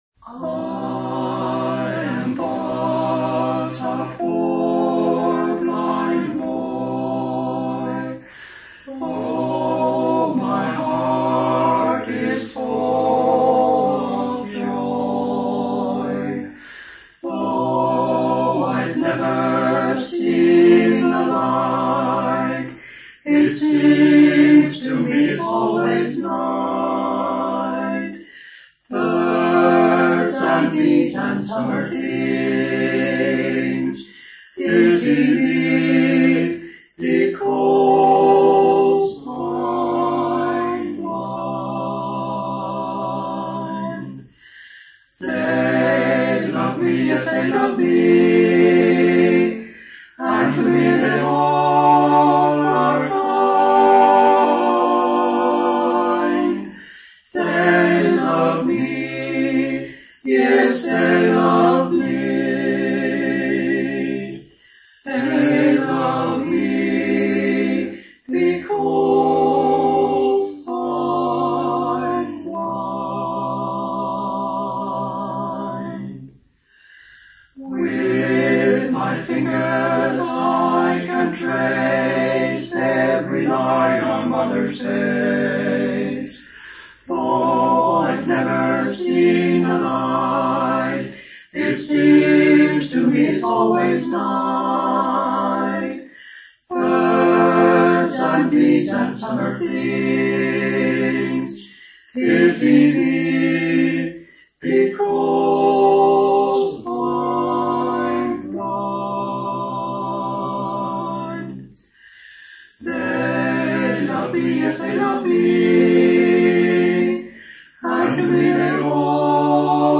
The 1960's St Keverne Quartet sing Blind Boy Jolly Roger Steal Away Recordings made in the early 1960's